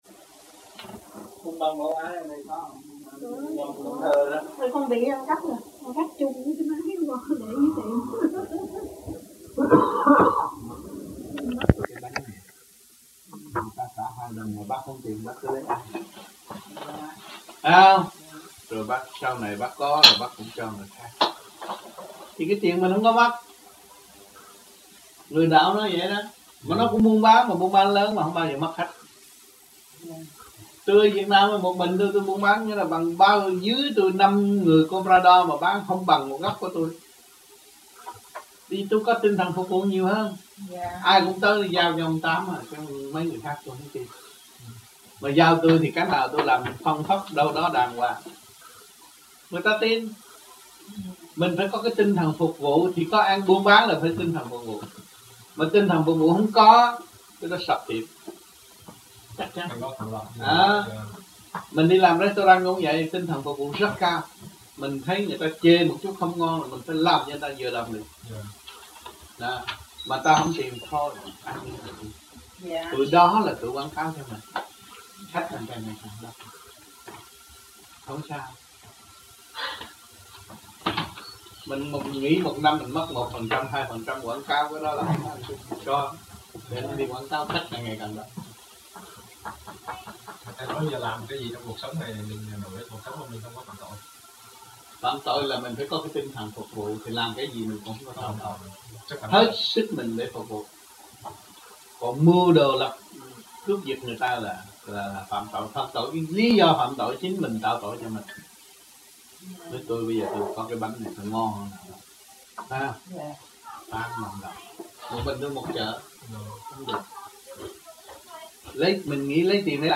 1985 Đàm Đạo